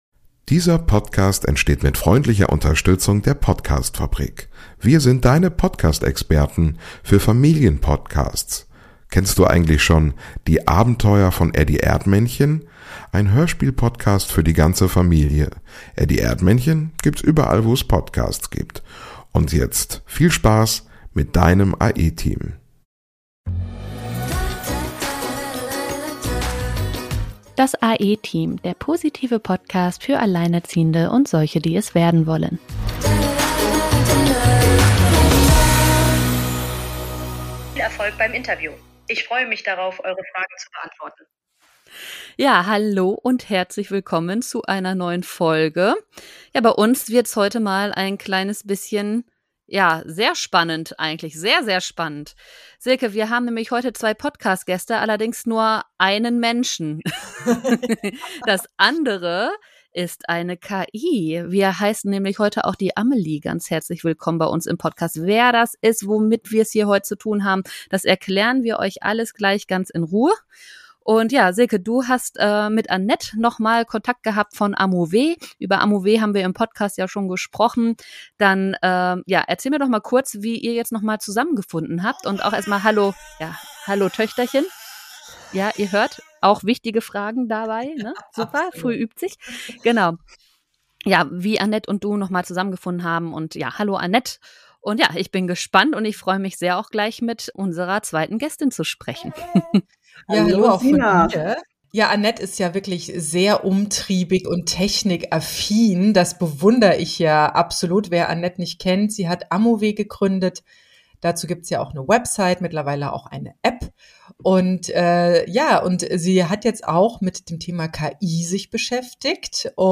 Wir sind zwei alleinerziehende Mütter, die beide ungeplant in die Situation gerutscht sind, als Single Moms ihren Alltag rocken zu müssen. Es geht um Schwierigkeiten, besondere Herausforderungen, Rechte und Pflichten, vor allem aber um viel positiven Input, emotionale Unterstützung und Optimierung des Mindsets von Alleinerziehenden für Alleinerziehende.